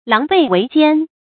注音：ㄌㄤˊ ㄅㄟˋ ㄨㄟˊ ㄐㄧㄢ
狼狽為奸的讀法